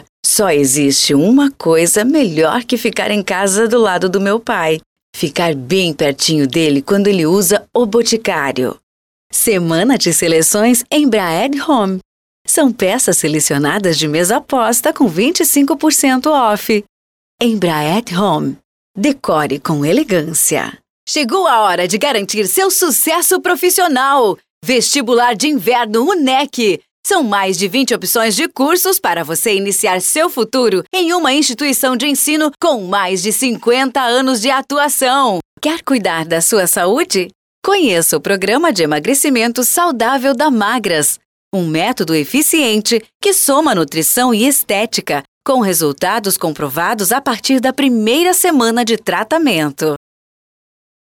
VOZES FEMININAS
Estilos: Padrão Institucional